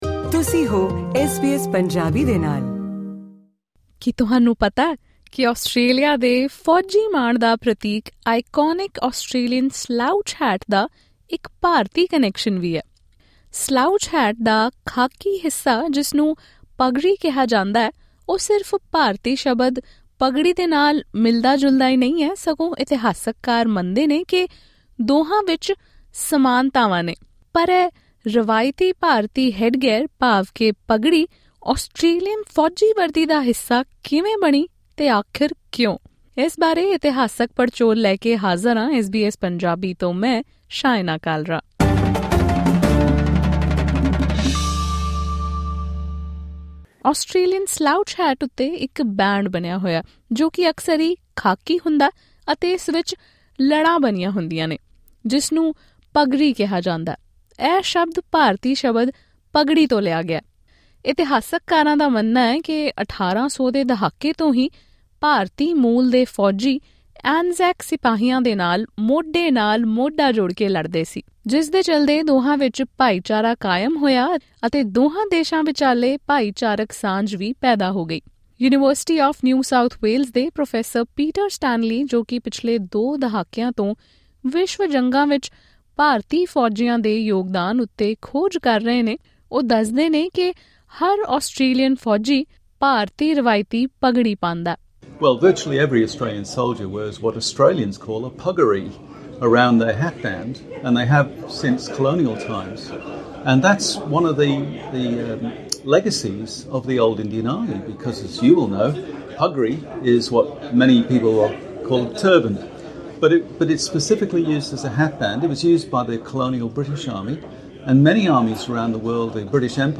Many historians believe this khaki element of the slouch hat was influenced by contact between Australian and Indian soldiers during colonial campaigns and World War I. Join us for this special podcast episode, where Indian and Australian historians explore the fascinating journey of the traditional Indian headgear and its influence on Australian military style.